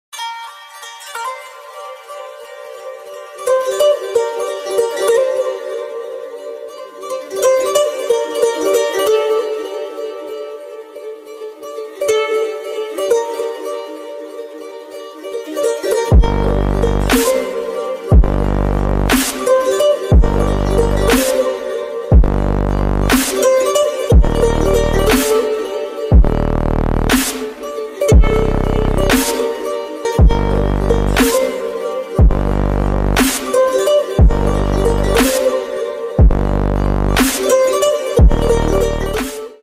Ремикс
громкие # без слов